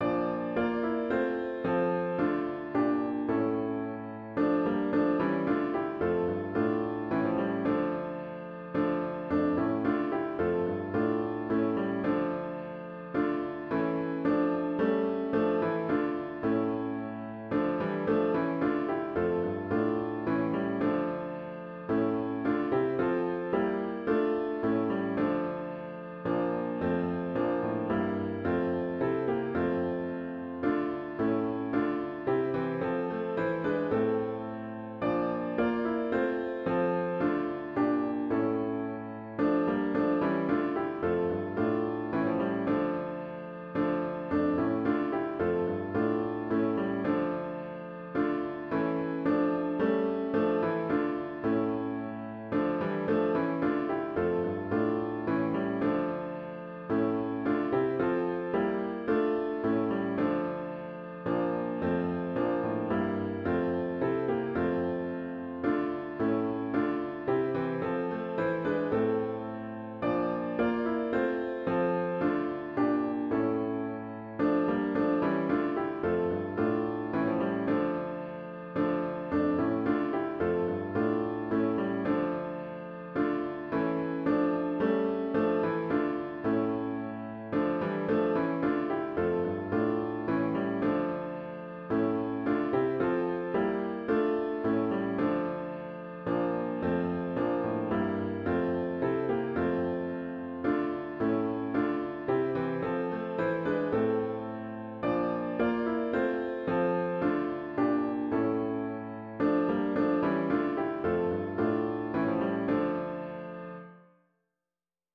*HYMN “Source and Sovereign, Rock and Cloud” GtG 11  Words: Thomas H. Troeger, 1986.